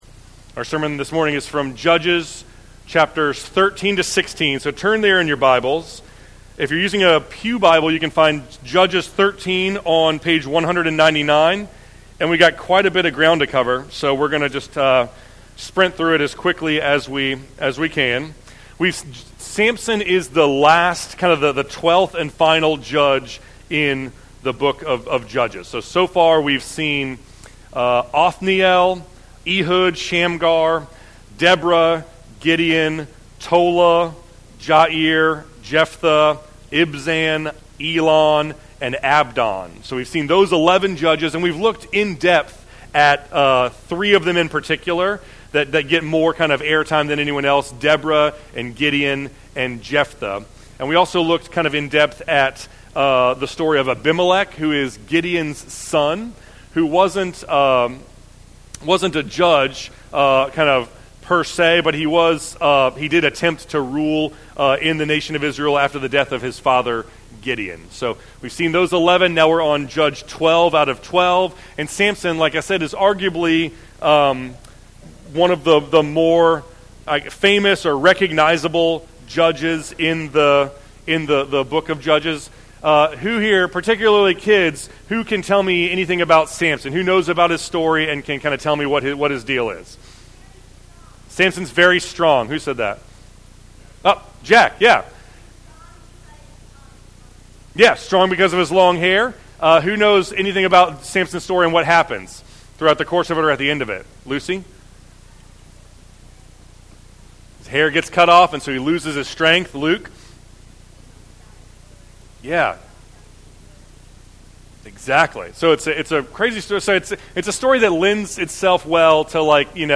Sermons | James River Community Church